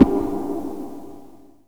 RIFFGTR 17-L.wav